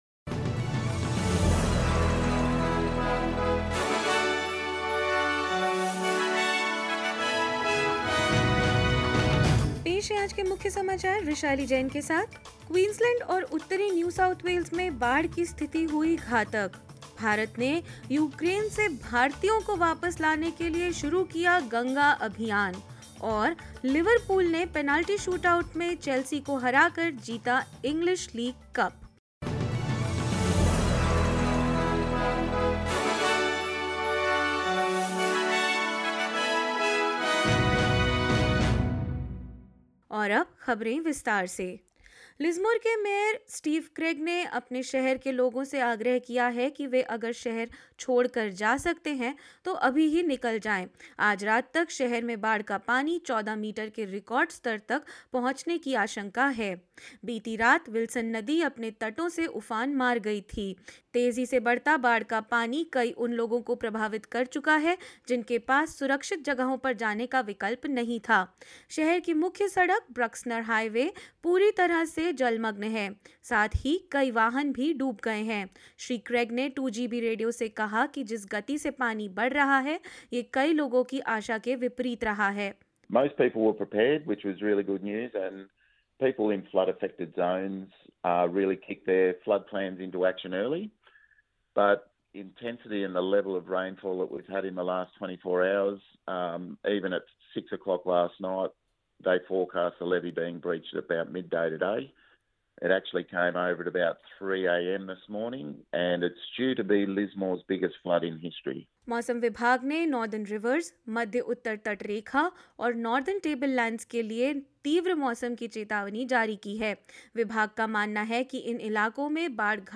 hindi_news_2802.mp3